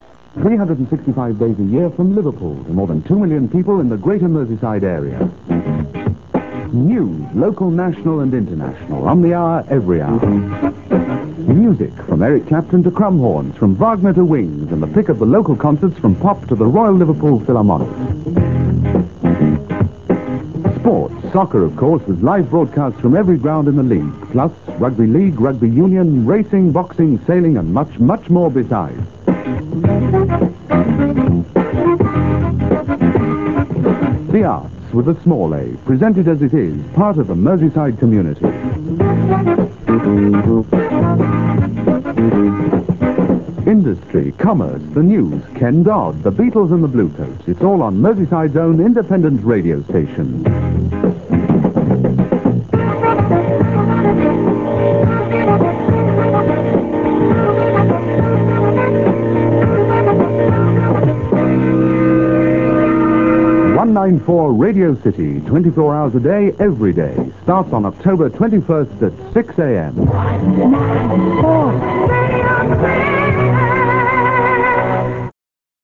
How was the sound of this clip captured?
In Late 1974 Radio City (Liverpool) commenced test transmissions in preparation for their official opening in November. These tests were on 194m Mediumwave only (1548 Khz).